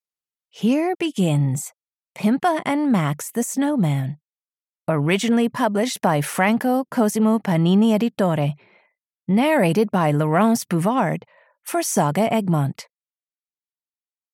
Pimpa and Max the snowman (EN) audiokniha
Ukázka z knihy